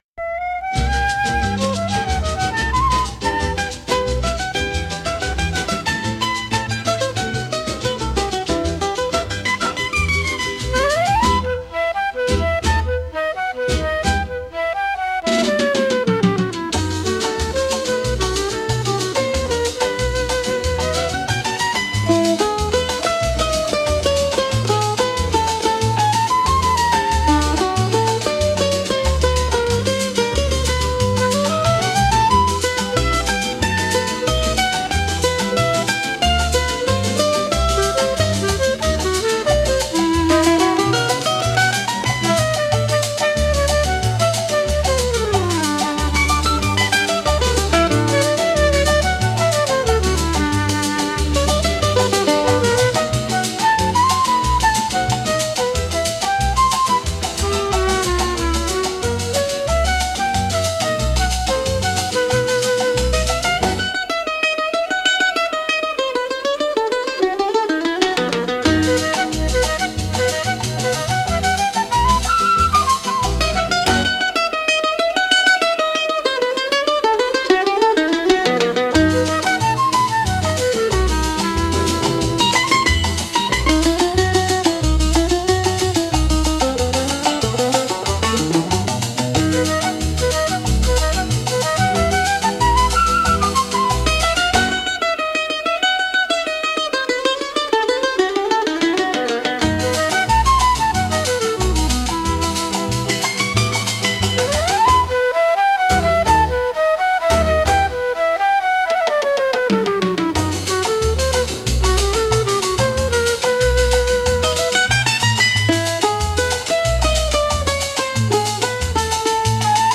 música e arranjo: IA) instrumental 7